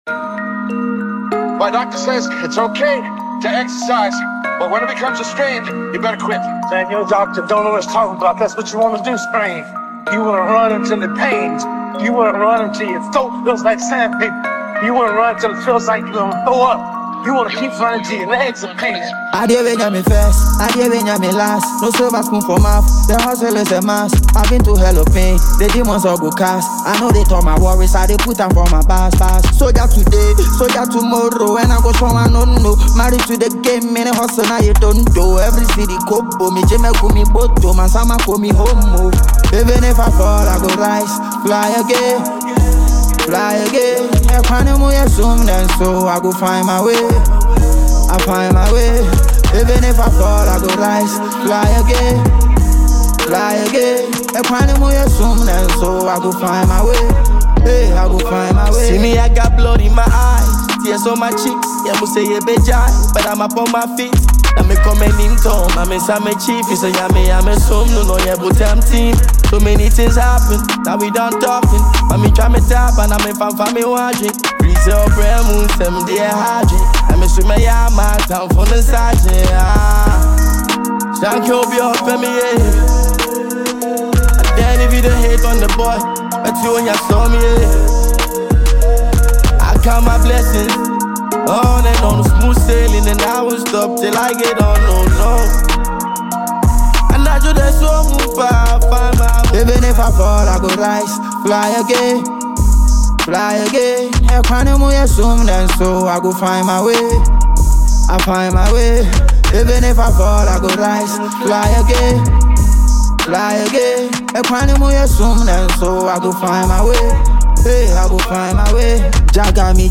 a Ghanaian trapper